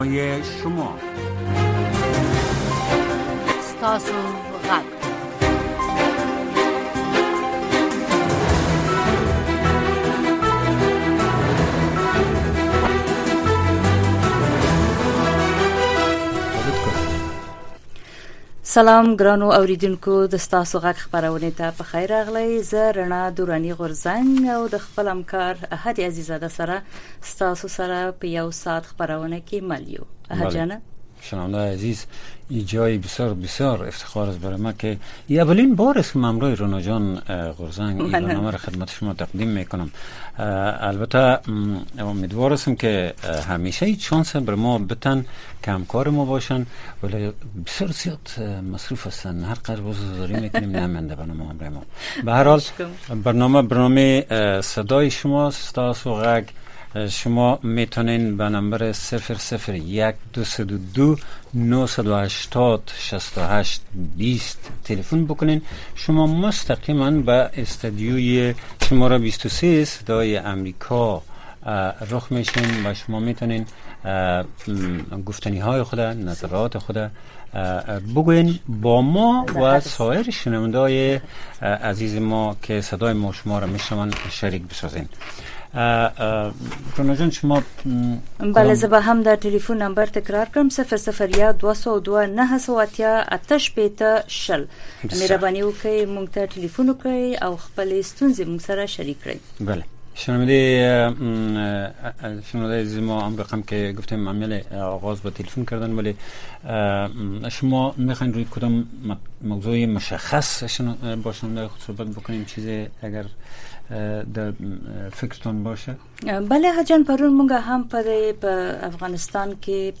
دا خپرونه په ژوندۍ بڼه د افغانستان په وخت د شپې د ۹:۳۰ تر ۱۰:۳۰ بجو پورې خپریږي.